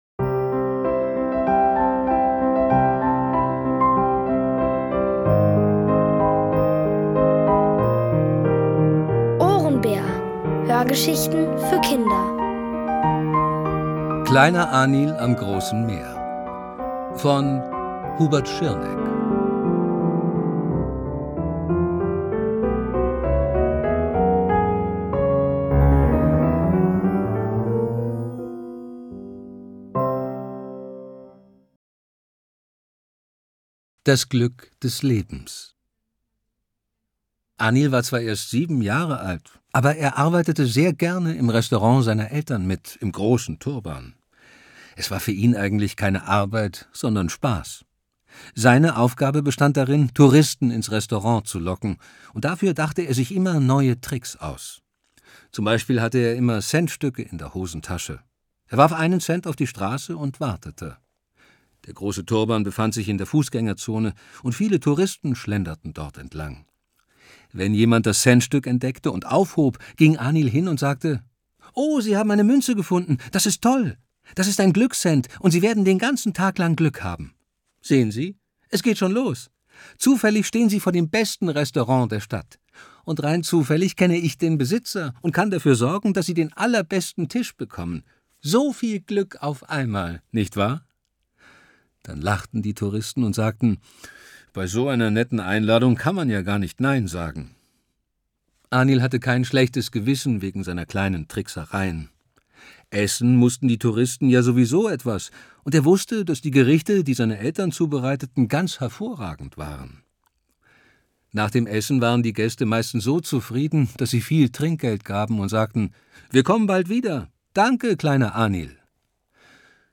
Aus der OHRENBÄR-Hörgeschichte: Kleiner Anil am großen Meer (Folge 5 von 7) von Hubert Schirneck.